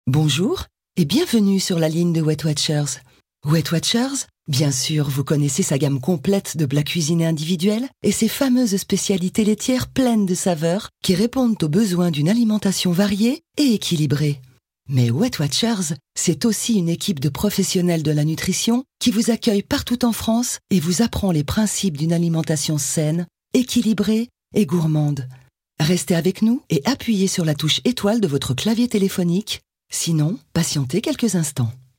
comédienne voix off / doublage
Kein Dialekt
Sprechprobe: Industrie (Muttersprache):